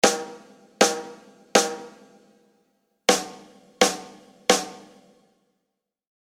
Drum-Tuning
Standard Hardrock-Sound
Dieser wird durch dominante Tief-Mitten sowie stark ausgeprägte Obertöne bestimmt. Die Ansprache des Snare-Teppichs soll schnell und kontrolliert sein, aber auch sensibel genug um in verschiedenen Dynamikstufen einwandfrei zur arbeiten.
Das Resonanz- und Schlagfell werden für einen solchen Sound sehr hoch gestimmt, sodass sie sich bei leichtem Daumendruck im Center kaum noch eindrücken lassen. Auch der Snareteppich wird sehr hart angespannt.